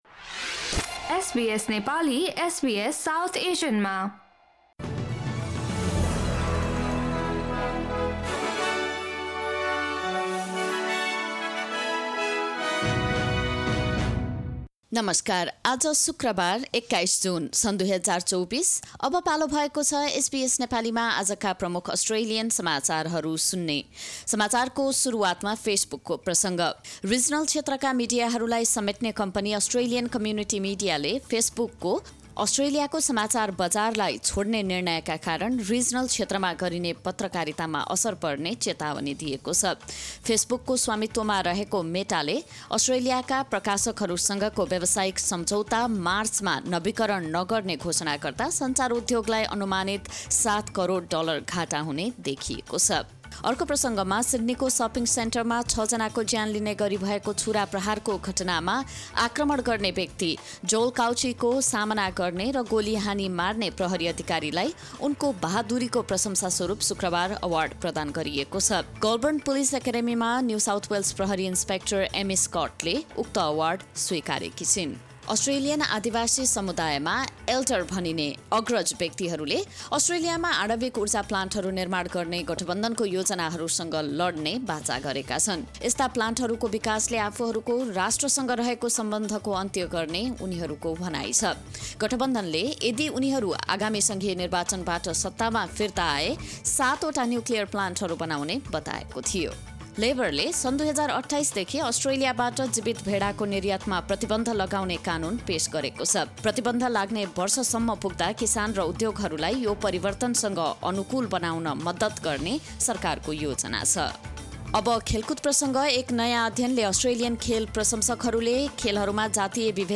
Listen to the latest bitesize top news from Australia in Nepali.